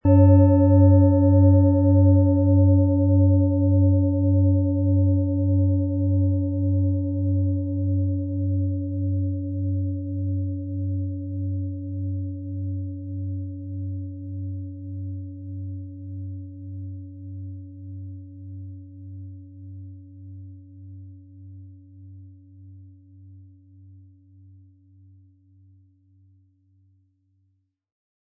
Tageston
Im mittleren Bereich klingt in dieser Schale außerdem der Merkur-Ton mit.
Der passende Klöppel ist kostenlos dabei, der Schlegel lässt die Klangschale harmonisch und wohltuend anklingen.